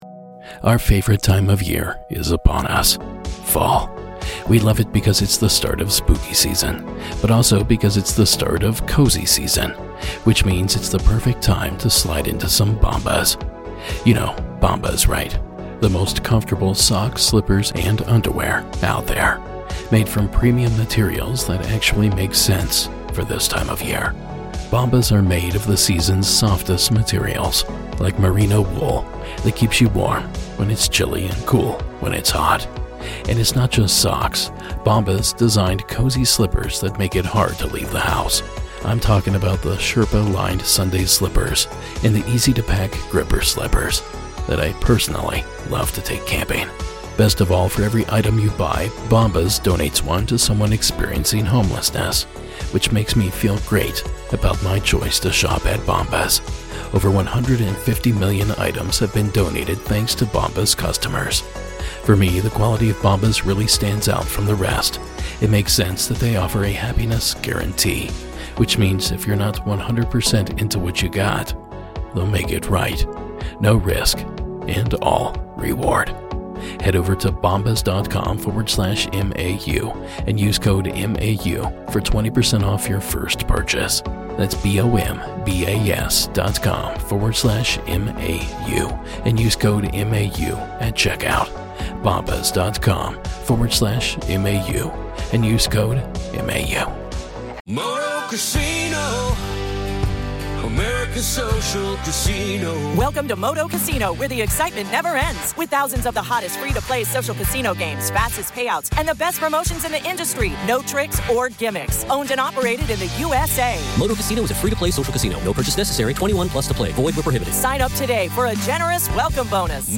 A frightening 911 call we couldn't shake from our minds, paired with stories we've received on the MAU hotline from the same state with similarities so chilling they just can't be ignored.
Season 19 Episode 50 of Monsters Among Us Podcast, true paranormal stories of ghosts, cryptids, UFOs and more, told by the witnesses themselves.